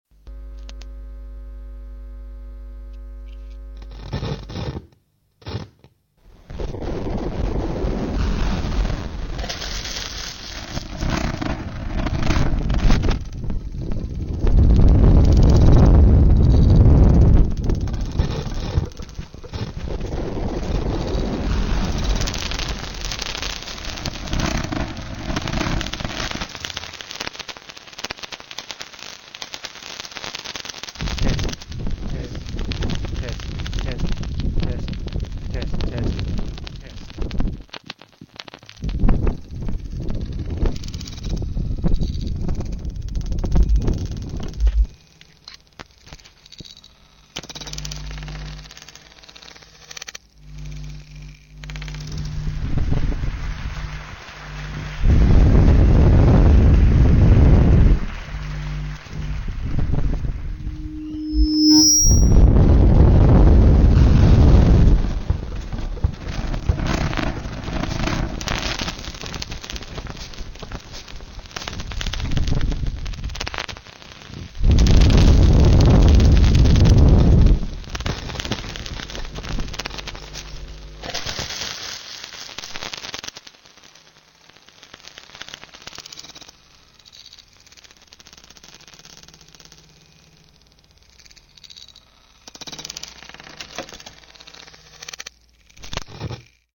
The work makes audible all the incidental and interfering noises that a wind shield usually absorbs.